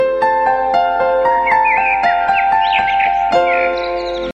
• Качество: 129, Stereo
звуки птиц
Красивый рингтон смс с пением птиц